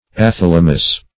Search Result for " athalamous" : The Collaborative International Dictionary of English v.0.48: Athalamous \A*thal"a*mous\, a. [Gr.
athalamous.mp3